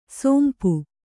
♪ sōmpu